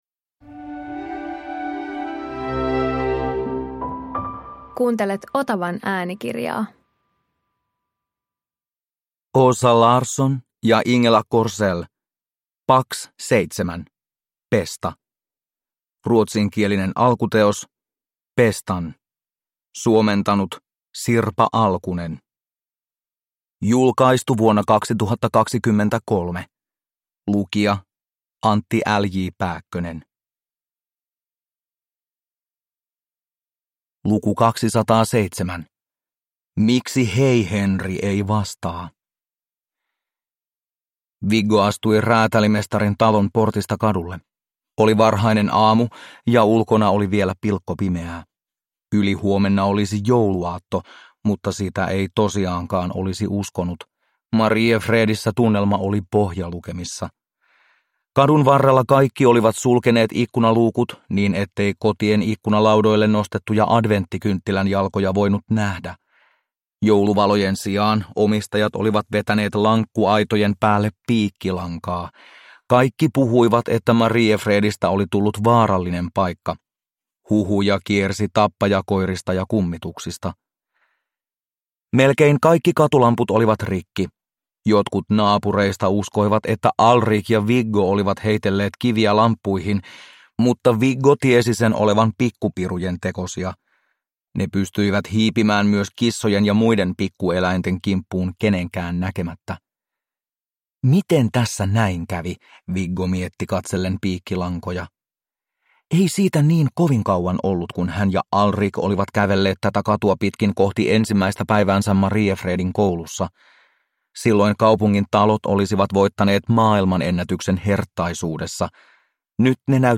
Pax 7 - Pesta – Ljudbok – Laddas ner